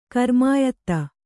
♪ karmāyatta